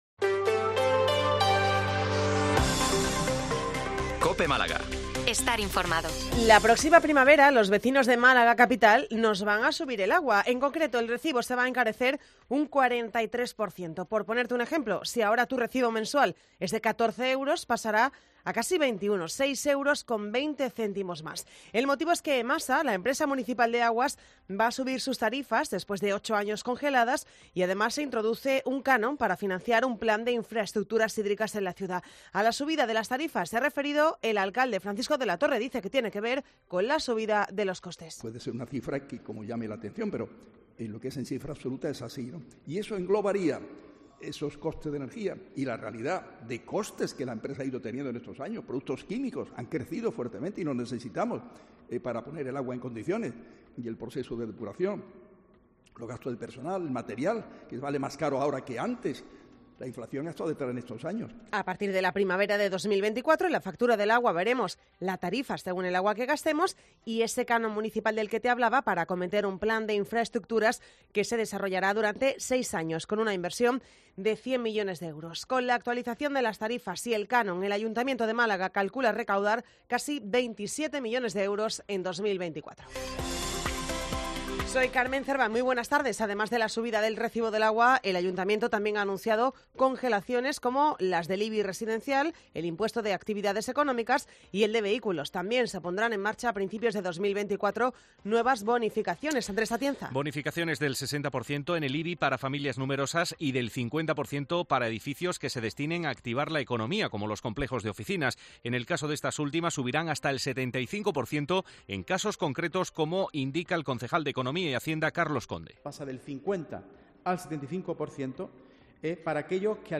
Informativo 14:20 COPE Málaga 120923